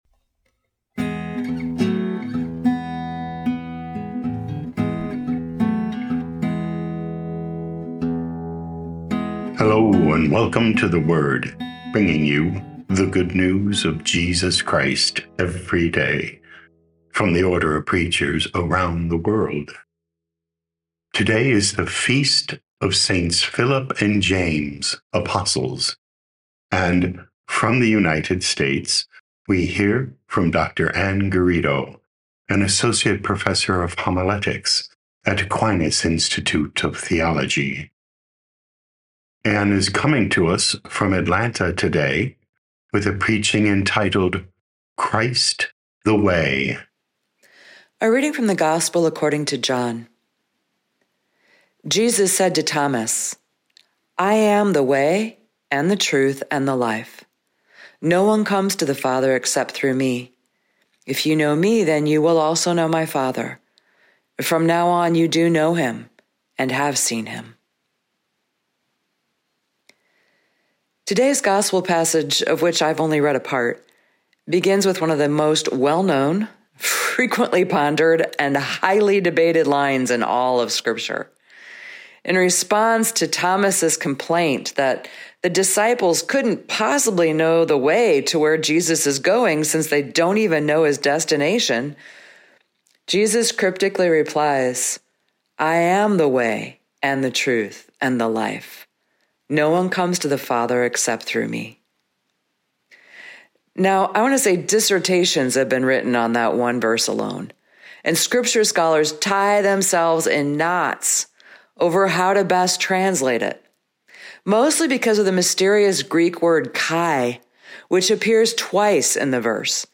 theWord – daily homilies from the Order of Preachers